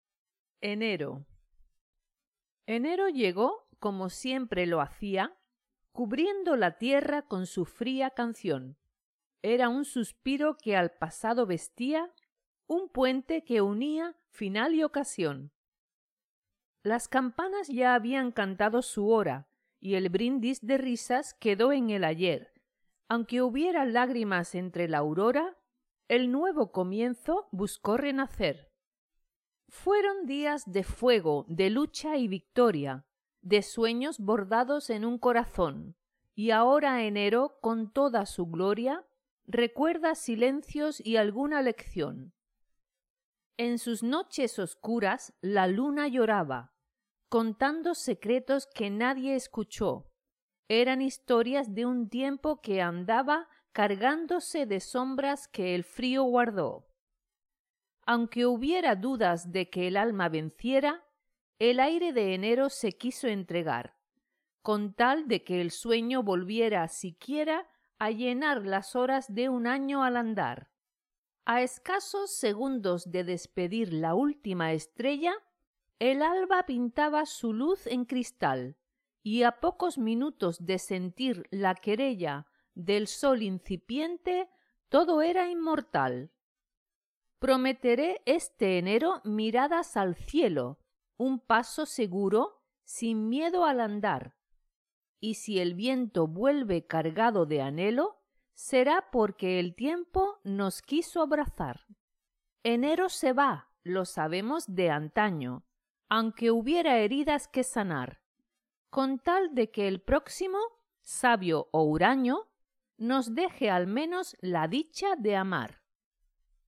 Spanish online reading and listening practice – level C1